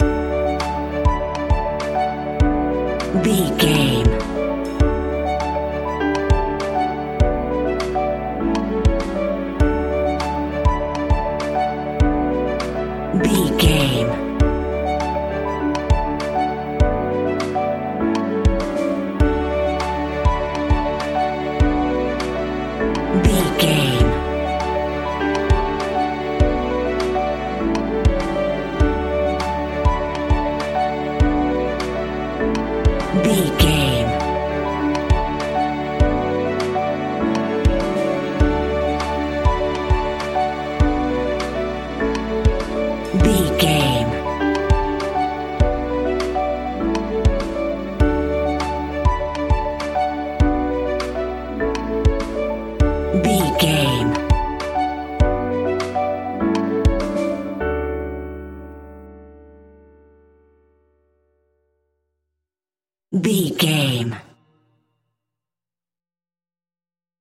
Ionian/Major
D
indie pop
energetic
uplifting
upbeat